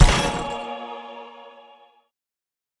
Media:heal_station_spell_01.wav 技能音效 spell 治疗台放置音效
Heal_station_spell_01.wav